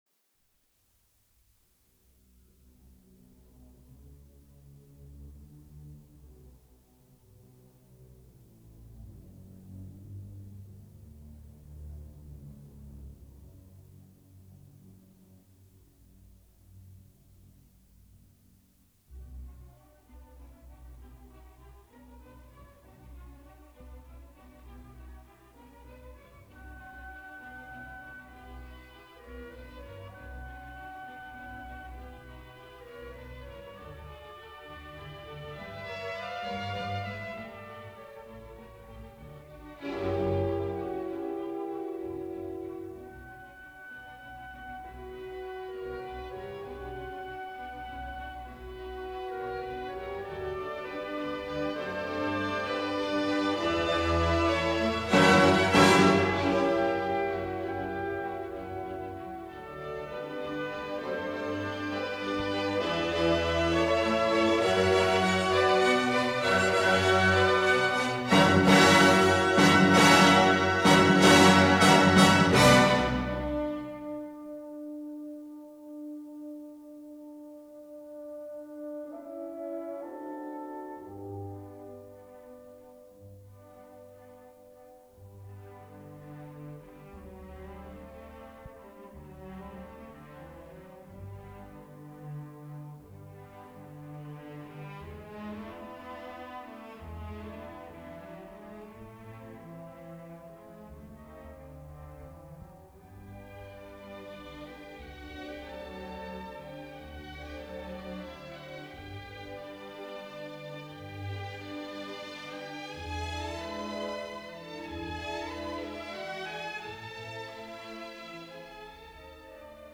Recorded at the Sofiensaal in Vienna.